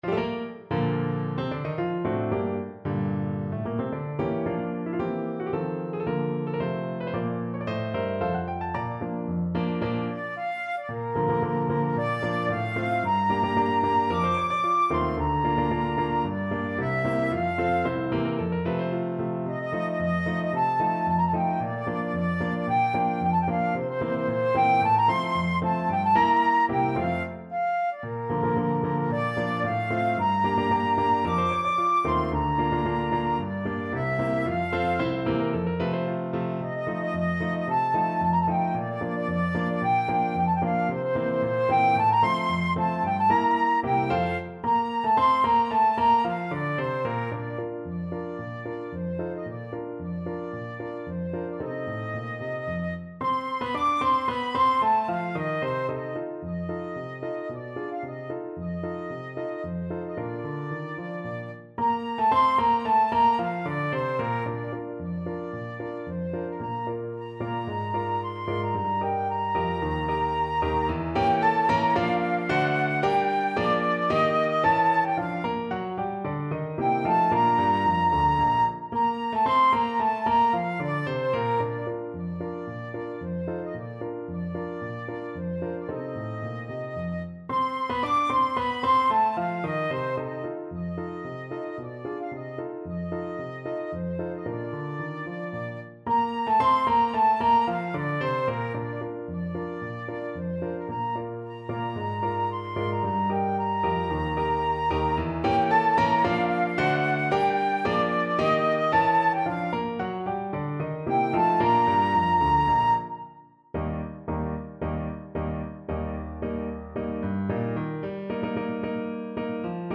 Flute
Bb major (Sounding Pitch) (View more Bb major Music for Flute )
March =c.112
2/2 (View more 2/2 Music)
Classical (View more Classical Flute Music)